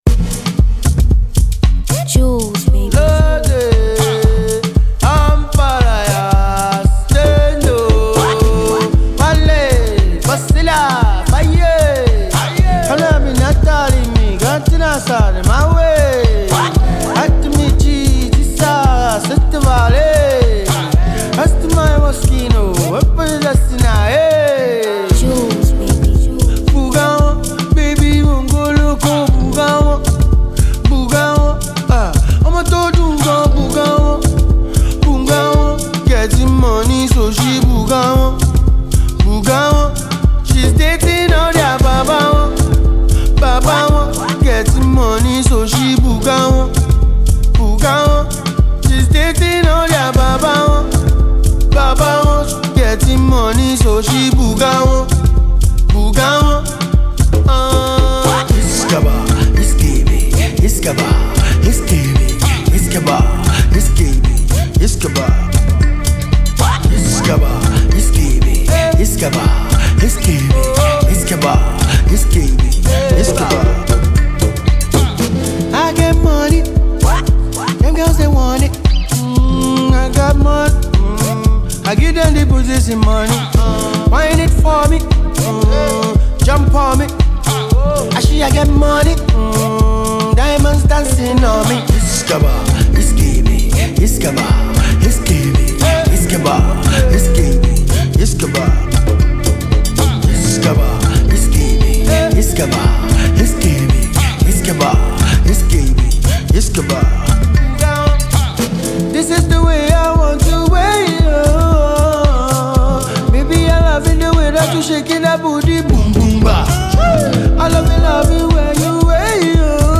soft afro beat